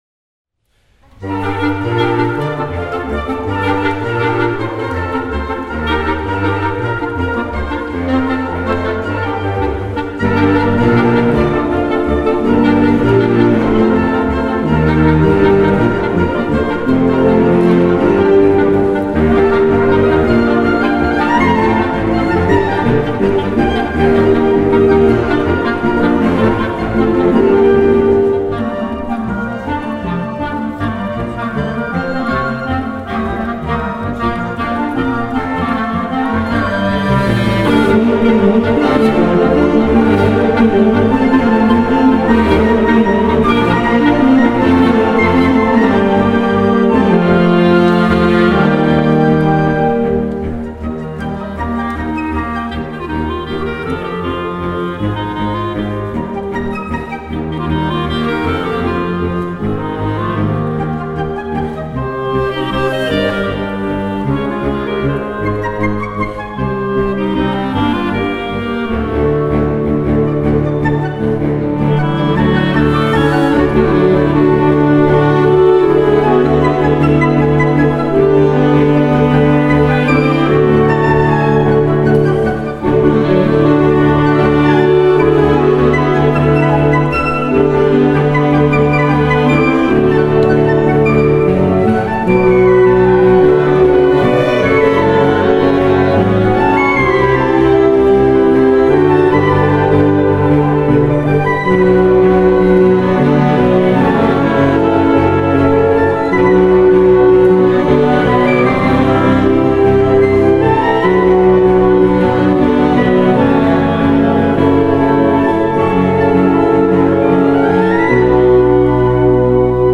Voicing: 12 Woodwinds